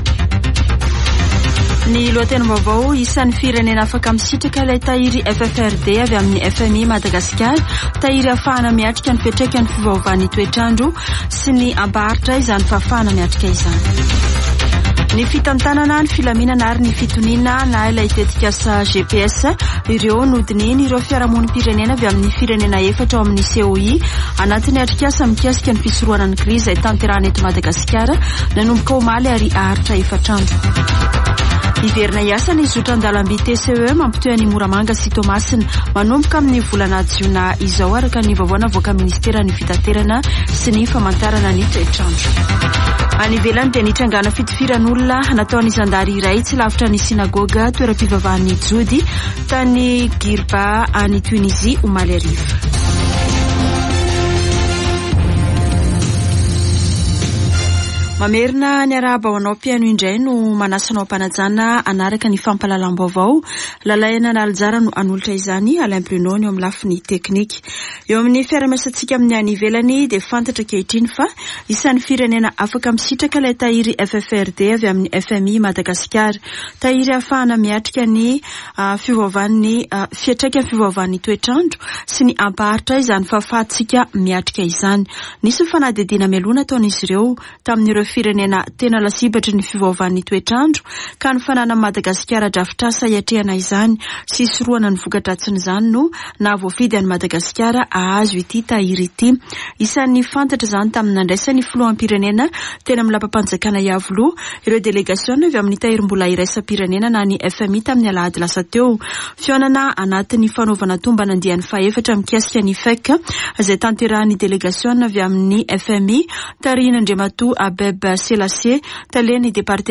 [Vaovao antoandro] Alarobia 10 mey 2023